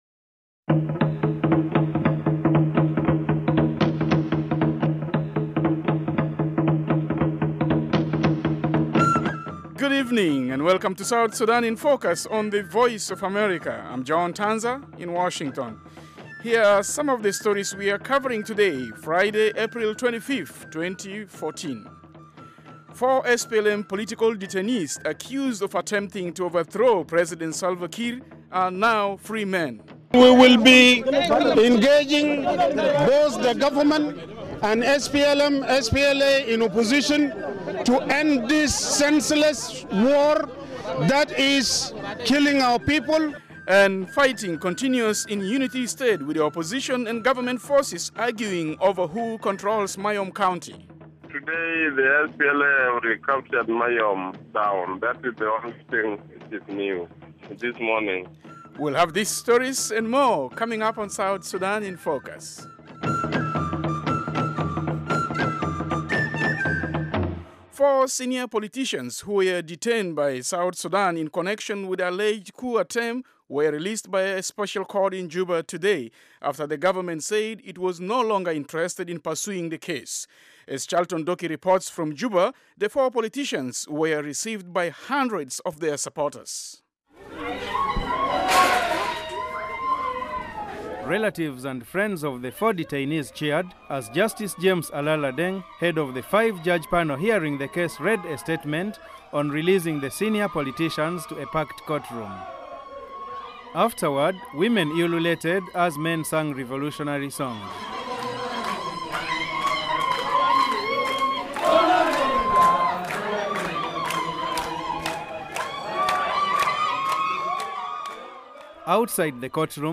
South Sudan in Focus is a 30-minute weekday English-language broadcast/internet program covering rapidly changing developments in the new nation of South Sudan and the region.